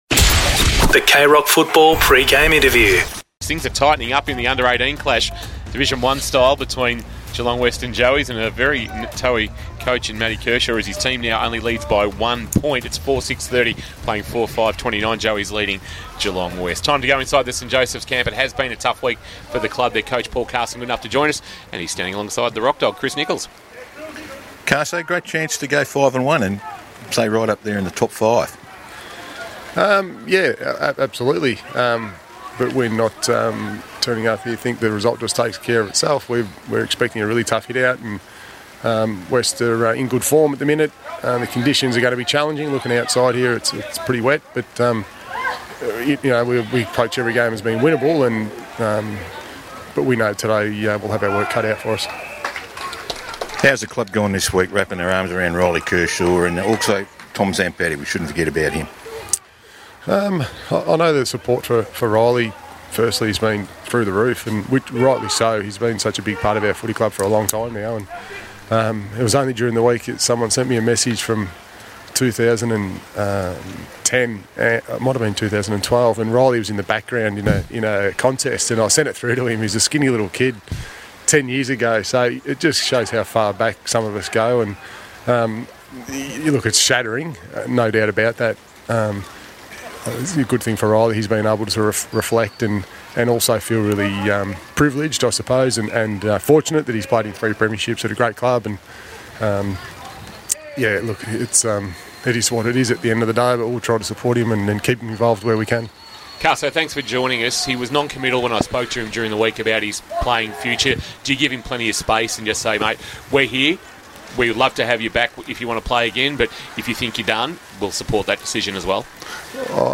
2022 - GFL ROUND 6 - GEELONG WEST vs. ST JOSEPH'S: Pre-match Interview